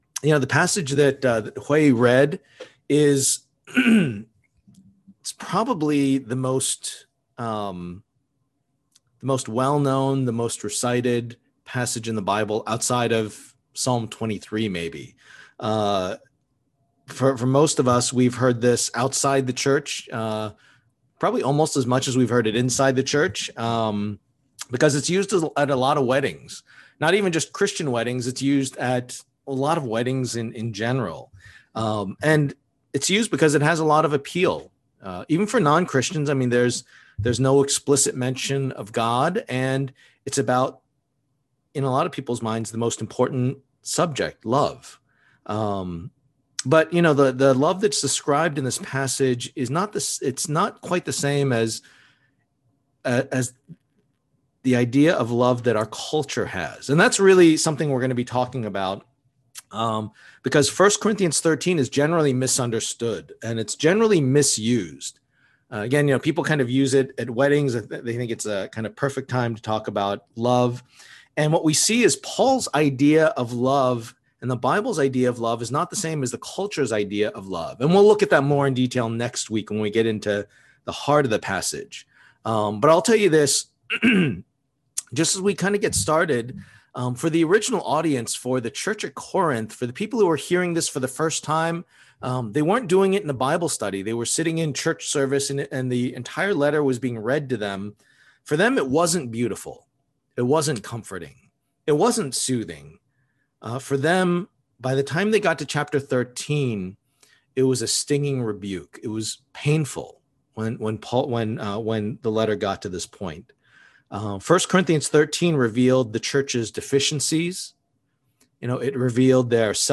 Passage: 1 Corinthians 13:1-13 Service Type: Lord's Day